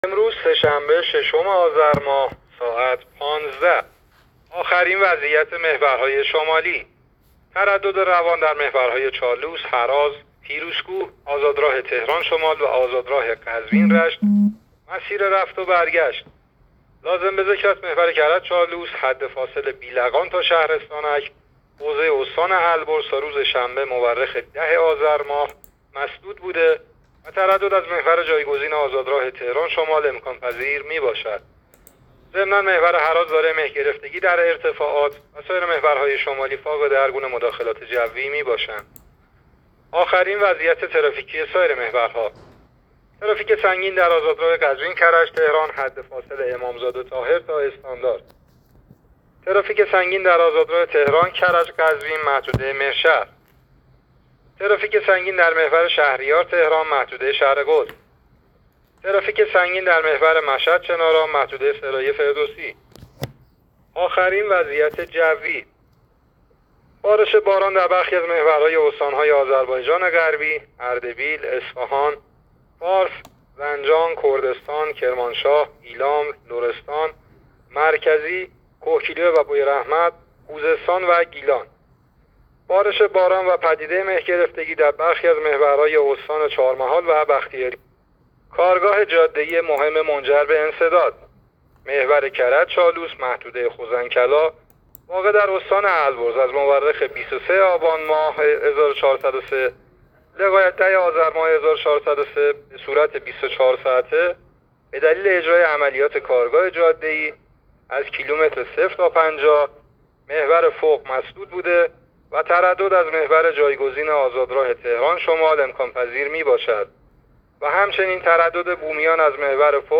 گزارش رادیو اینترنتی از آخرین وضعیت ترافیکی جاده‌ها تا ساعت ۱۵ ششم آذر؛